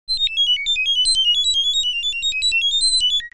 جلوه های صوتی
دانلود صدای ربات 38 از ساعد نیوز با لینک مستقیم و کیفیت بالا